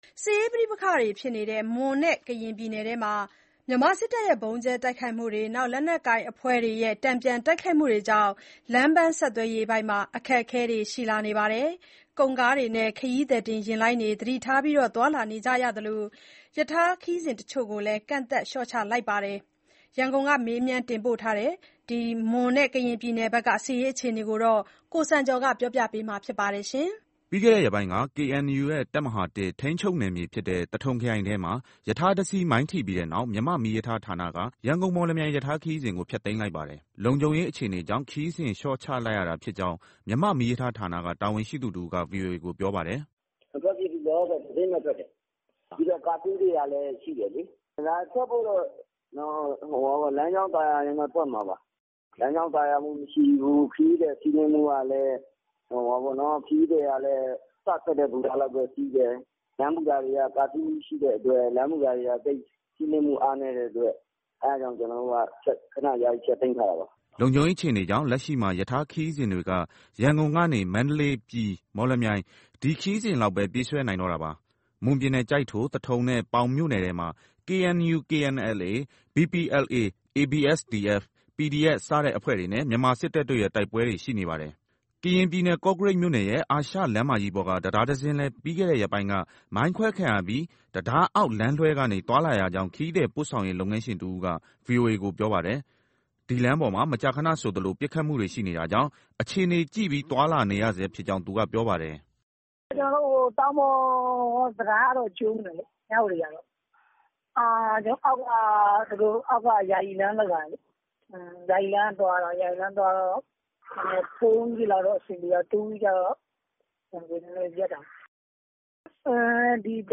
(မီးရထားဌာနတာဝန်ရှိသူတဦး)